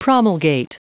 Prononciation du mot promulgate en anglais (fichier audio)